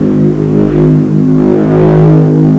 core_beam.wav